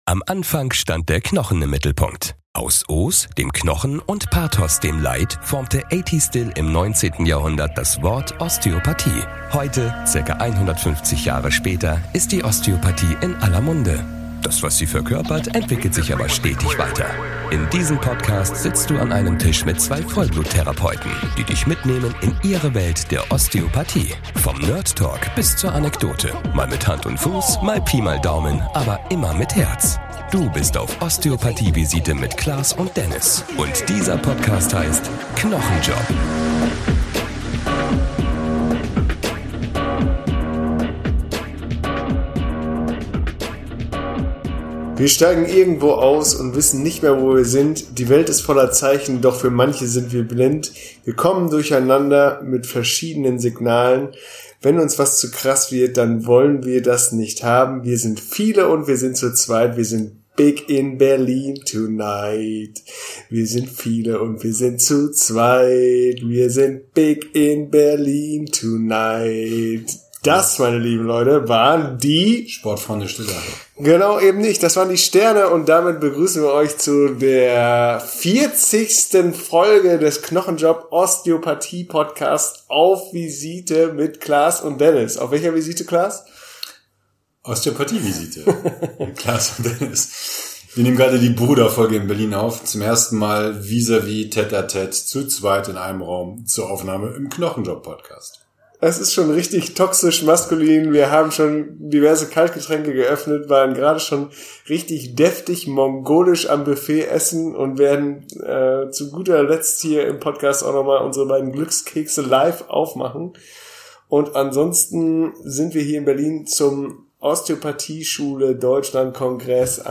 Dieses Mal wurde unter neuen Vorraussetzungen aufgenommen: Zu zweit in einem schwülen Hotelzimmer an einem Mikro und ggf. bei dem einen oder anderen Erfrischungsgetränk.